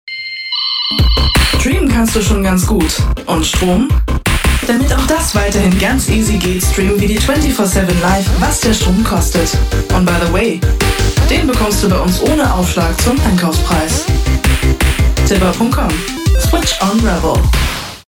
Porsche Panamera TV Spt
Dietmar Wunder (Berlin) spricht Twitch Dietmar Wunder ist bekannt als deutsche Stimme von Daniel Craig in James Bond - seit Casino Royale - (2006) und leiht seine Stimme u.a. den Kollegen Adam Sandler, Cuba Go... mehr daten auswählen